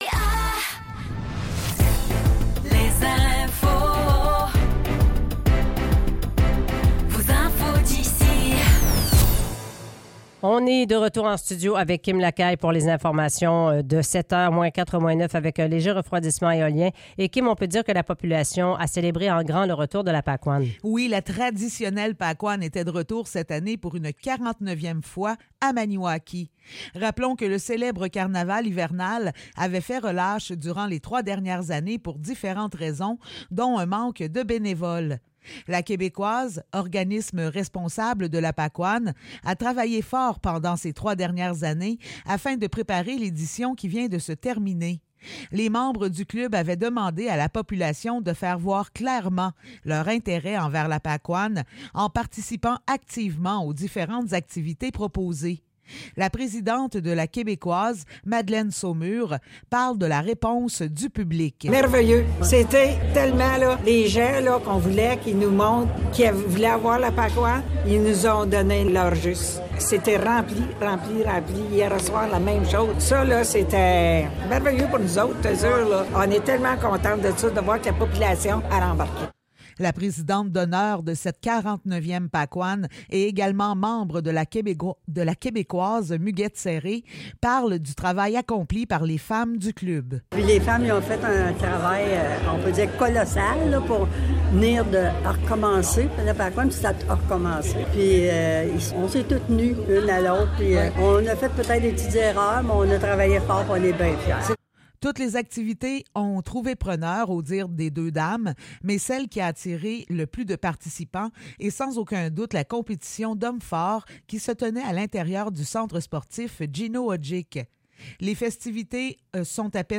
Nouvelles locales - 29 janvier 2024 - 7 h